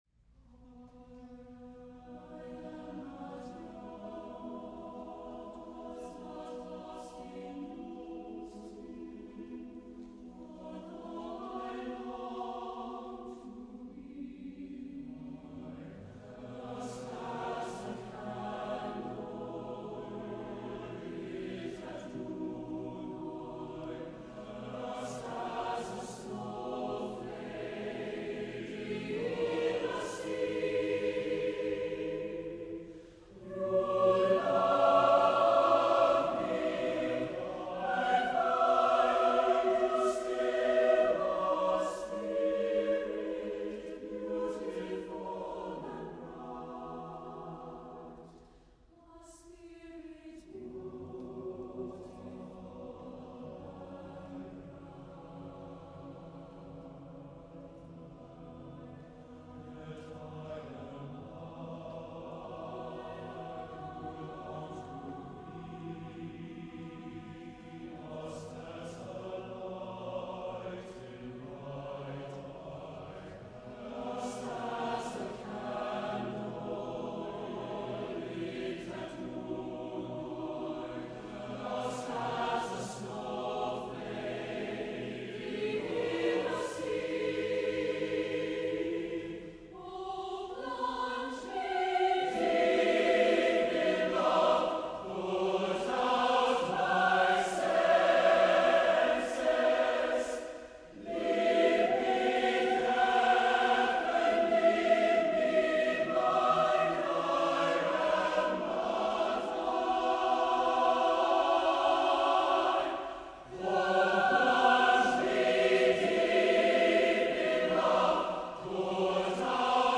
Accompaniment:      With Piano
Music Category:      Choral
A lush, deeply emotive setting